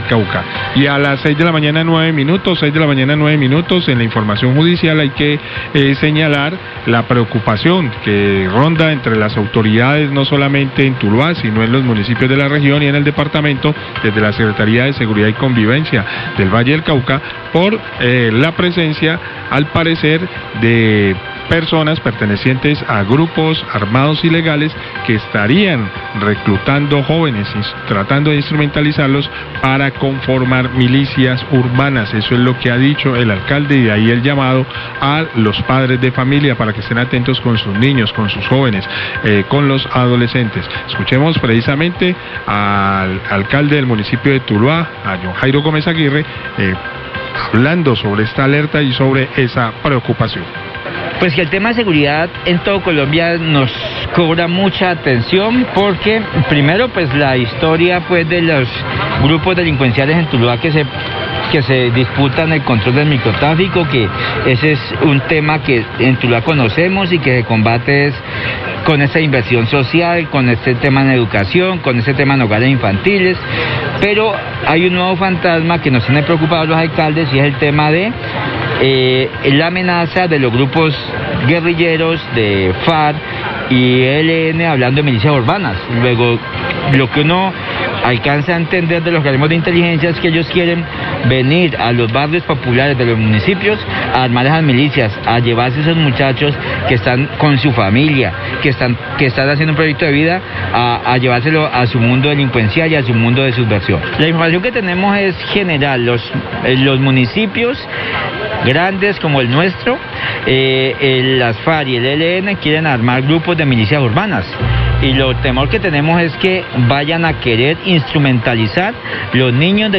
Radio
Alerta en los municipios del departamento del Valle del Cauca por el reclutamiento de menores por parte de grupos armados ilegales como las Farc y el Eln, esto con el propósito de formar milicias urbanas. Eel alcalde de Tuluá habla del tema y le pide a los padres estar muy atentos a lo que hacen sus hijos.